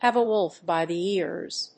アクセントhàve [hóld] a wólf by the éars